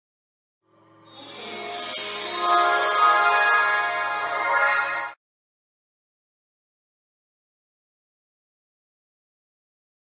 Звук радуги, появляющийся из ниоткуда